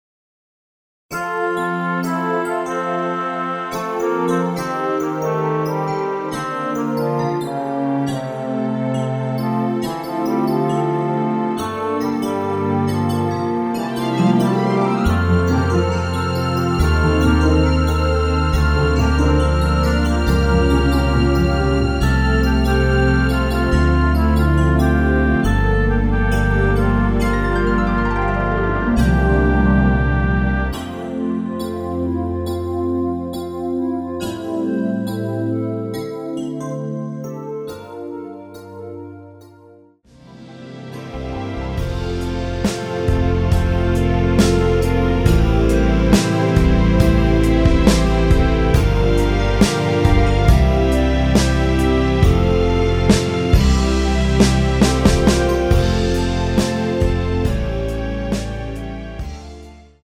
원키에서(-2)내린 멜로디 포함된 MR입니다.(미리듣기 확인)
멜로디 MR이라고 합니다.
앞부분30초, 뒷부분30초씩 편집해서 올려 드리고 있습니다.
중간에 음이 끈어지고 다시 나오는 이유는